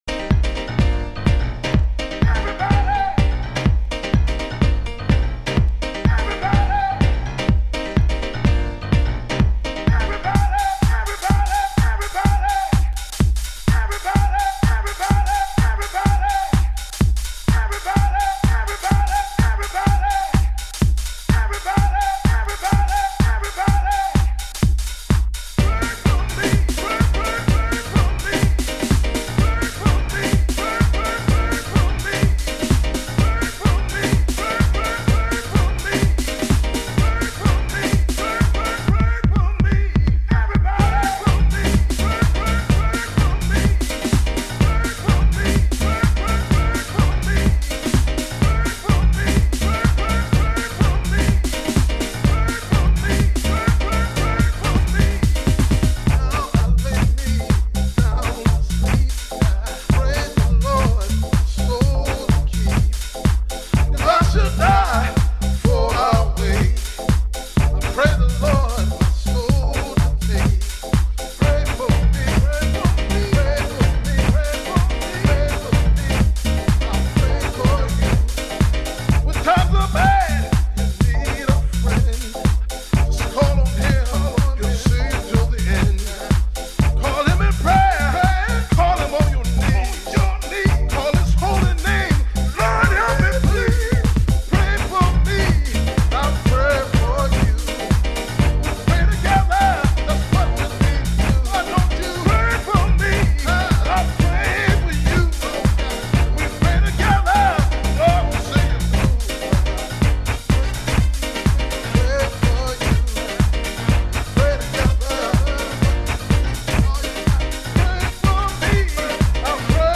Early House / 90's Techno
アーティスト名からも熱が伝わる、ディープなガラージハウス。